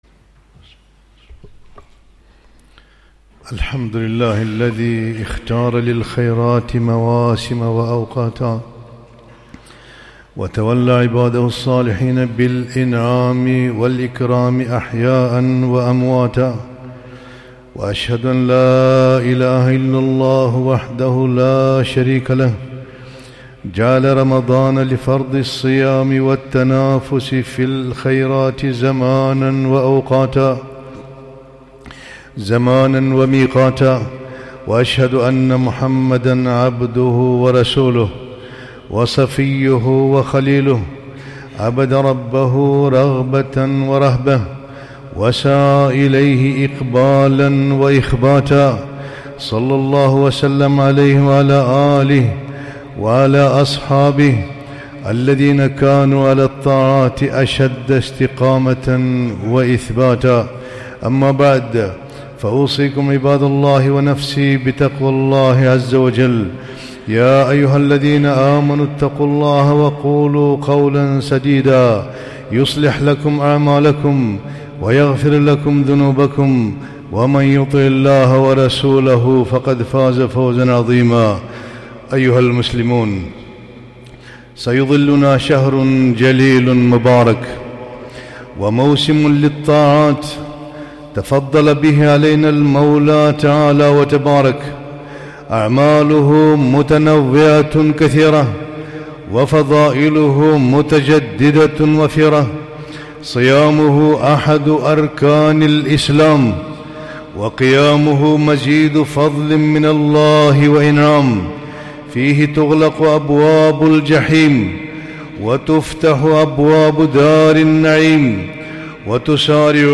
خطبة - استقبال شهر رمضان